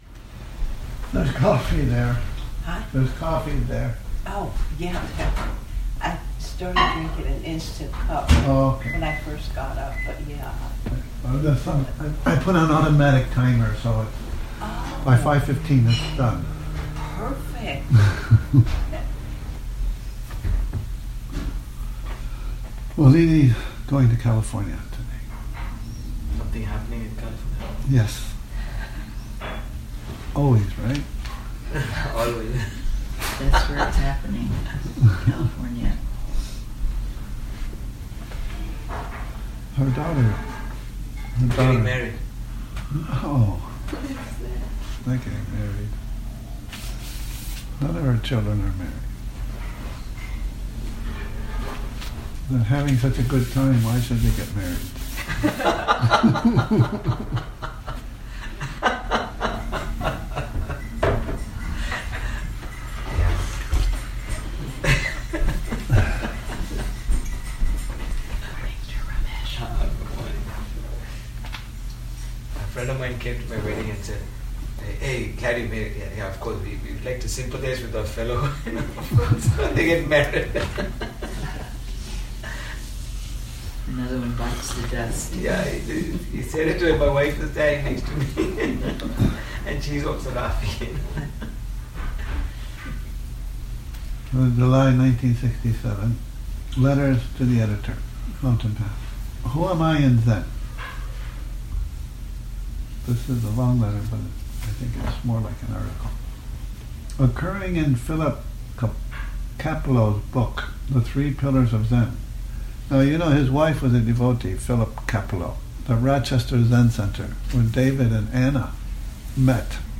Morning Reading, 27 Nov 2019